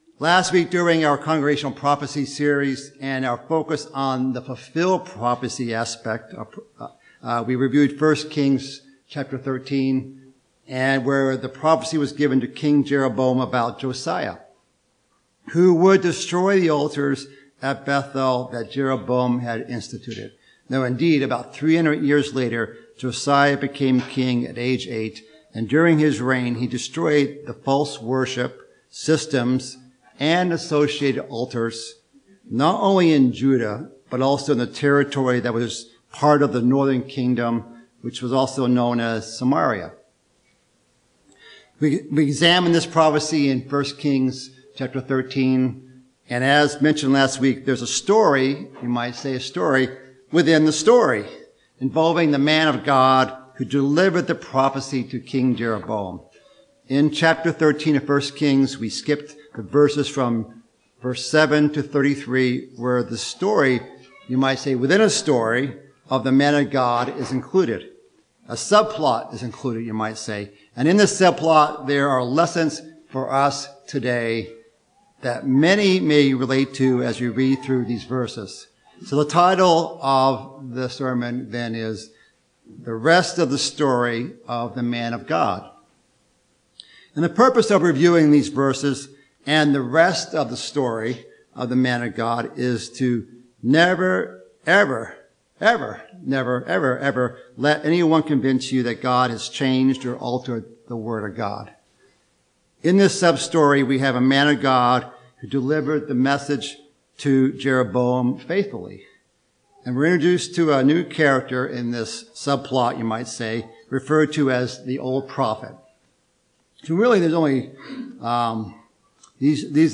Given in Northwest Indiana